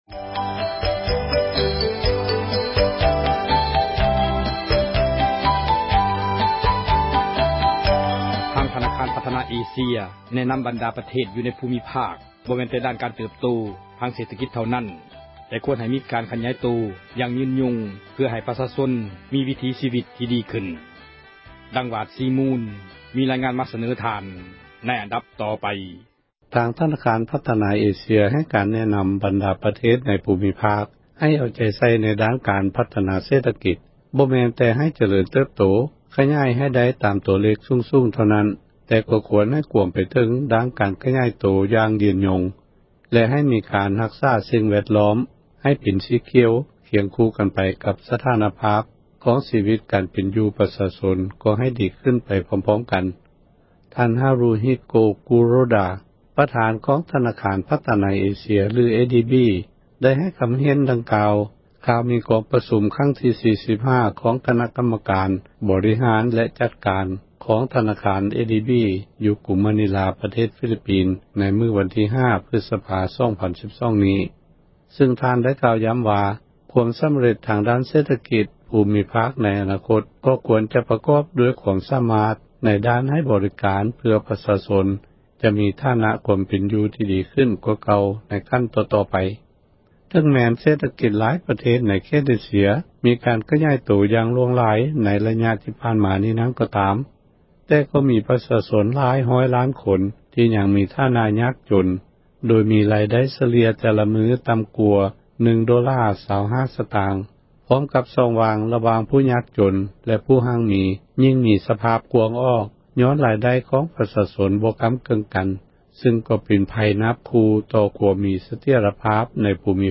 ພູມີພາຄເອເຊັຽ ແລະການພັທນາ — ຂ່າວລາວ ວິທຍຸເອເຊັຽເສຣີ ພາສາລາວ